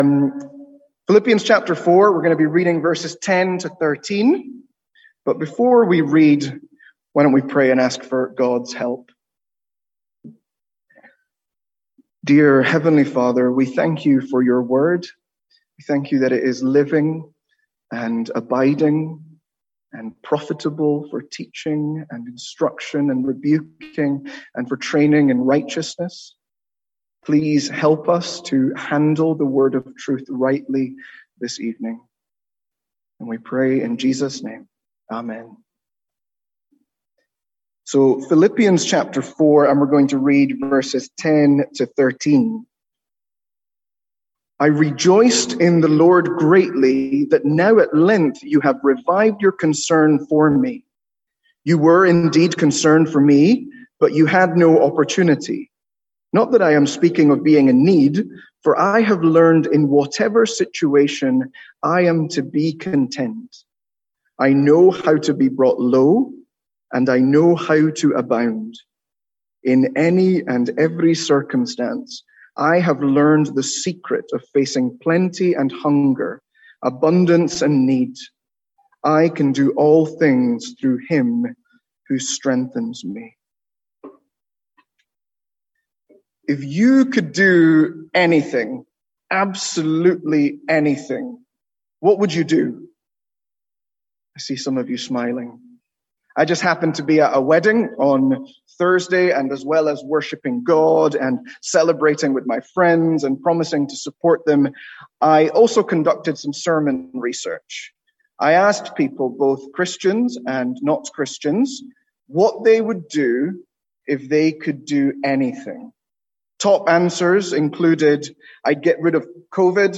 Sermons | St Andrews Free Church
From our evening series in "Calendar Verses"